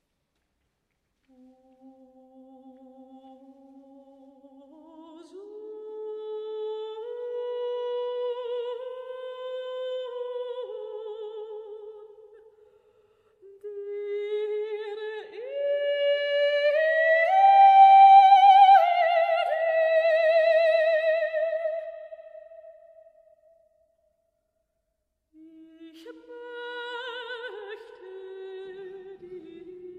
Neue Musik
Vokalmusik
Solostimme(n)